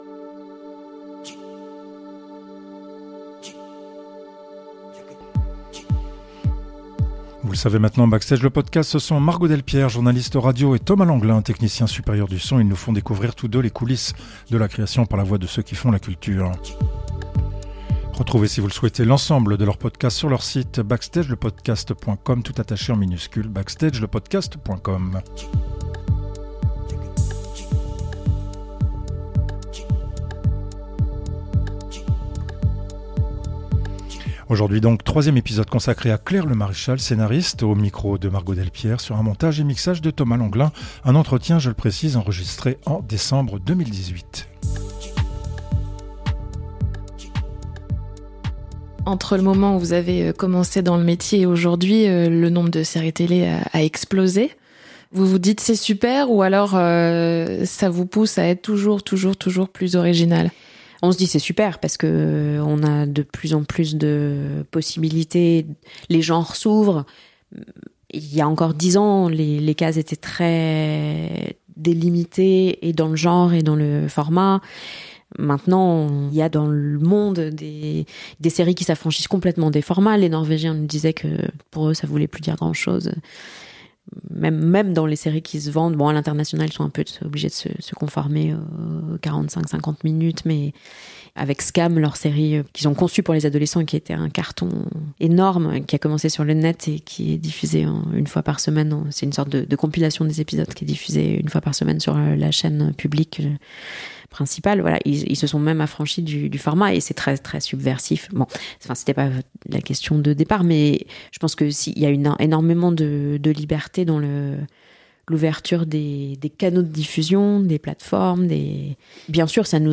Entretien enregistré en décembre 2018.